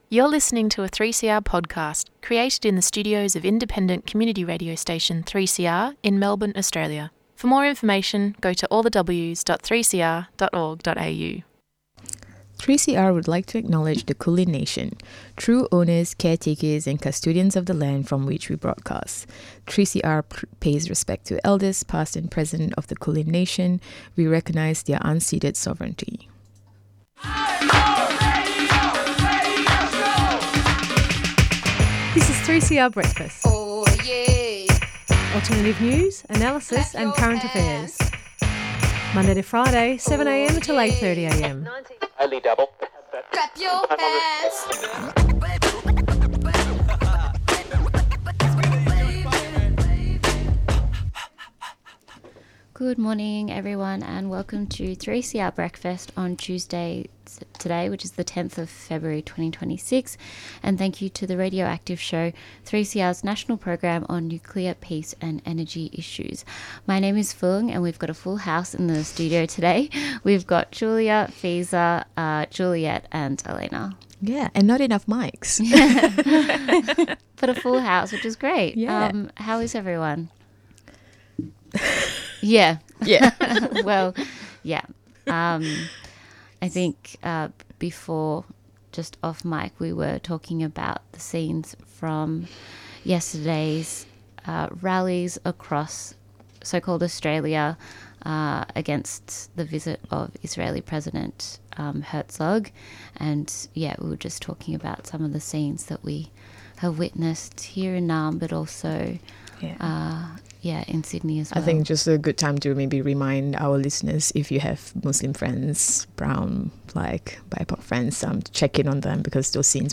7.05 am News headlines + Alt News on protesting, the races and Chile.